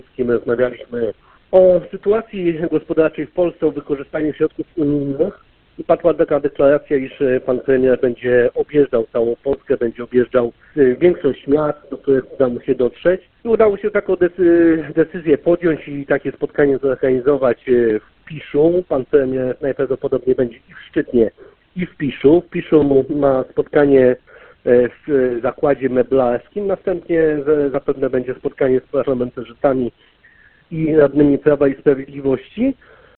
– Wizyta będzie miała charakter gospodarczy – tłumaczy poseł PiS Wojciech Kossakowski.